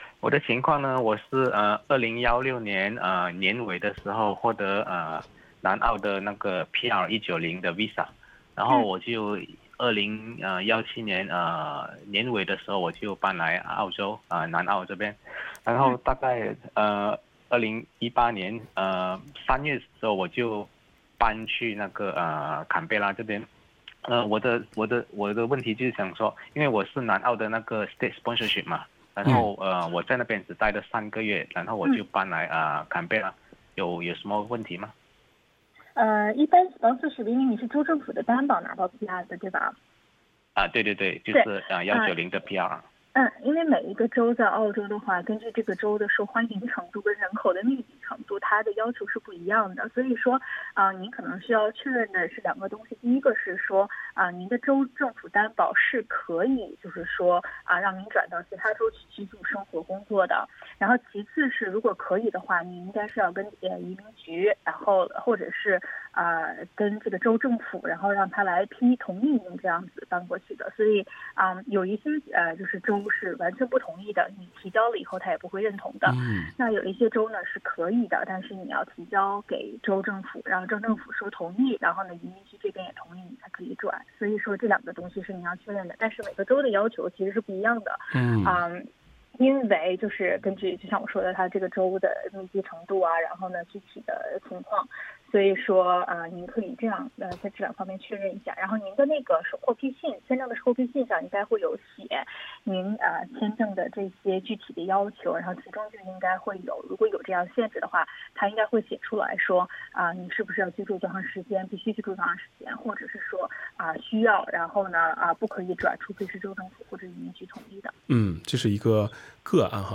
《现场说法》听众热线节目逢周二上午8点30分至9点播出。